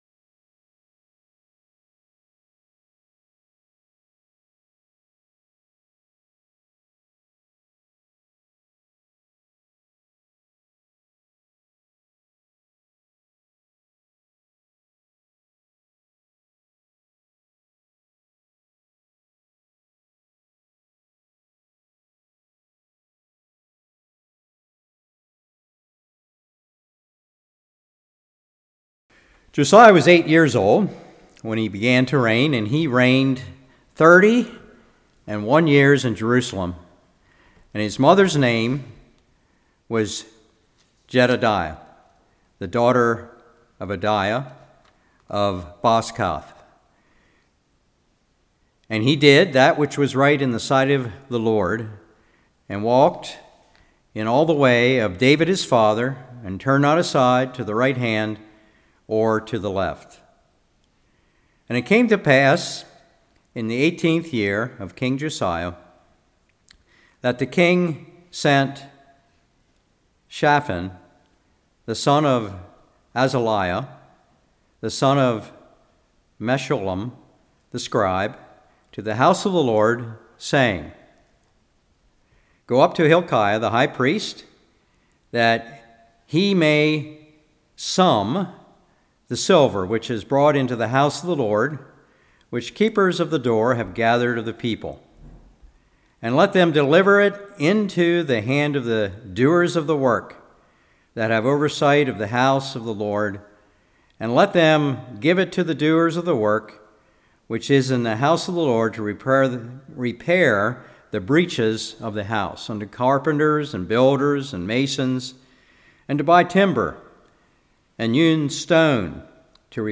II Kings 22 Service Type: Evening History of Israel